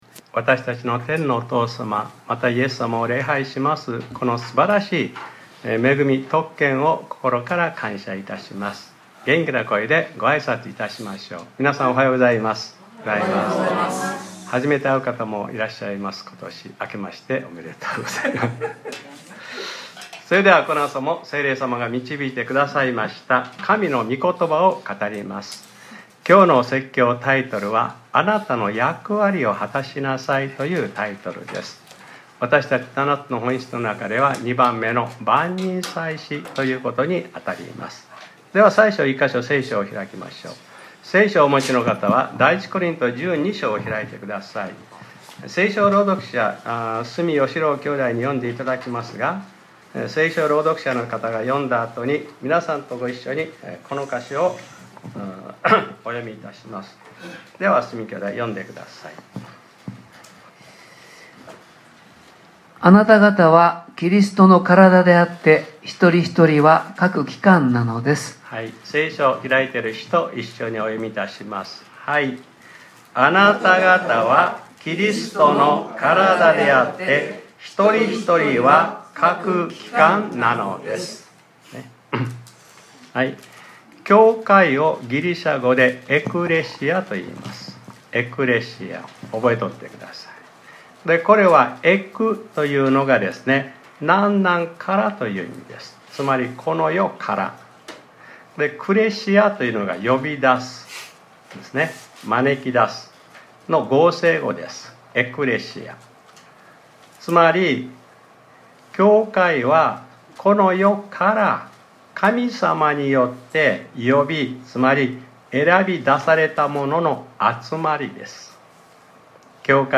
2025年01月12日（日）礼拝説教『 あなたの役割を果たしなさい 』 | クライストチャーチ久留米教会